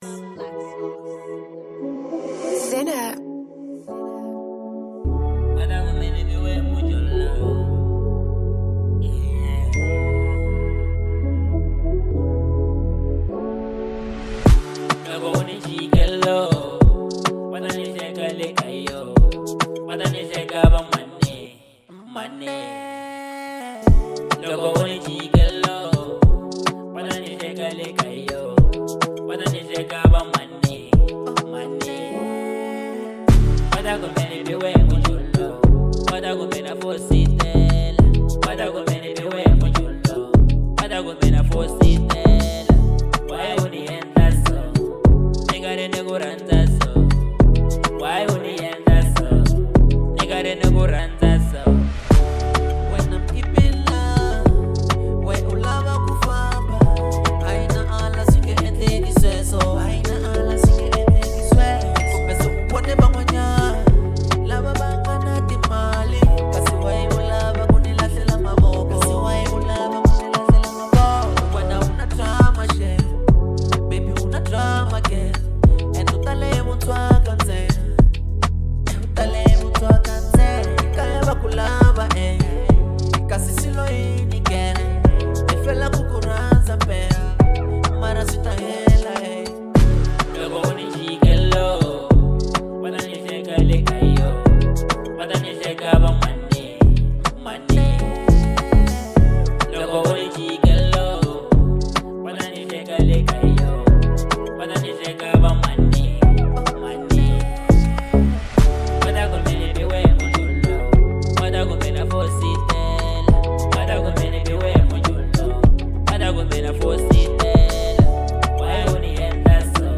02:43 Genre : Afro Pop Size